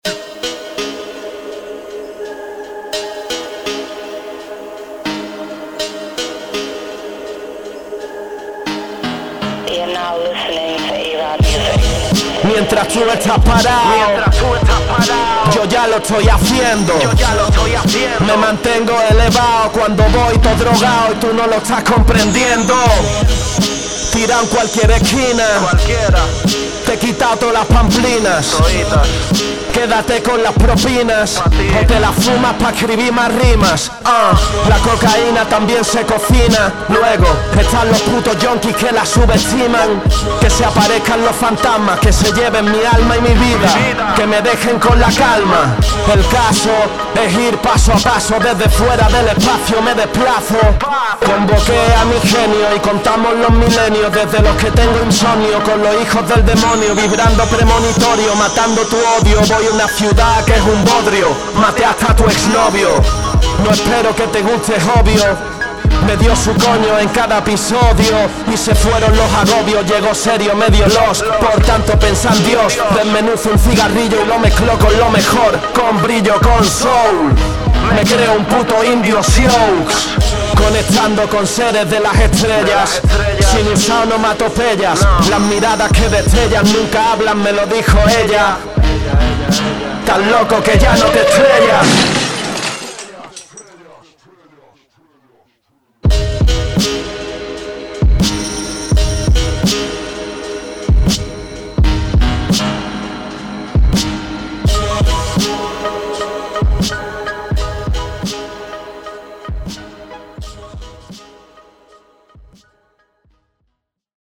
Está grabado, editado, mezclado y masterizado